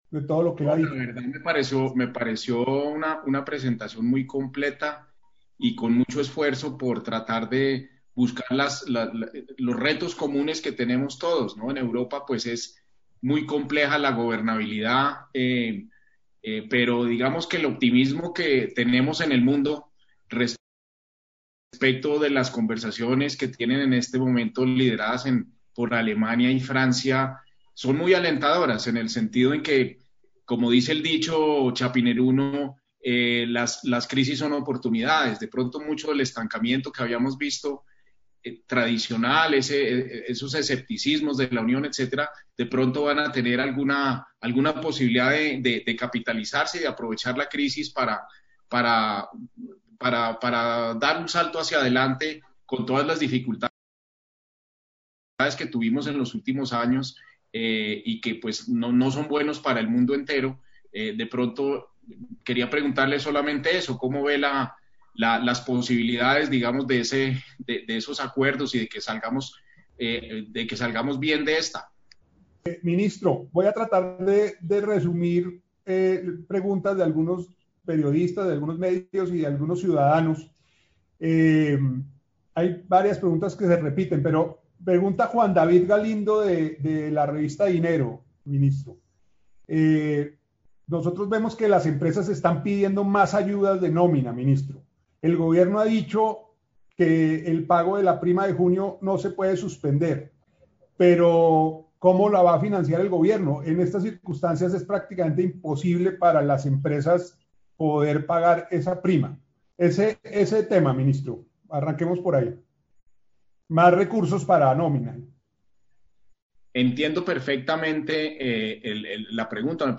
Stereo
ministro-panel-economia-bioseguridad-part2-1